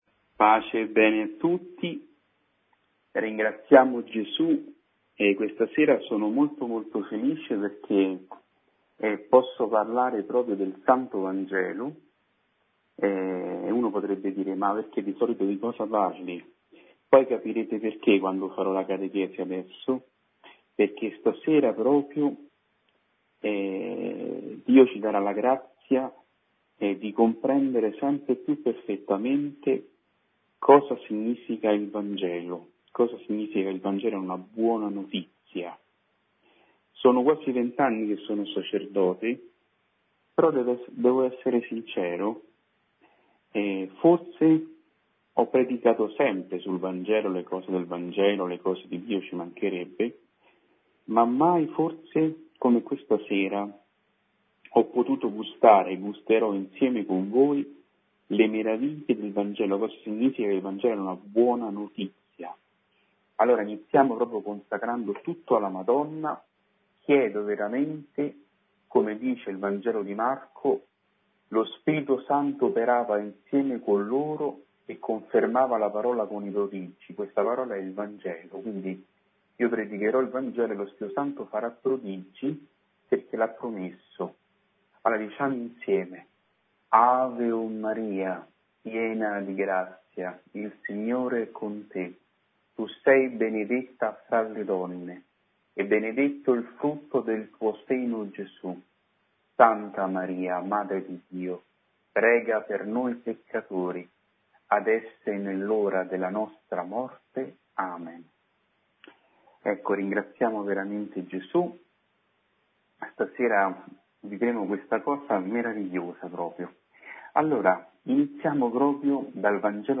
meditazione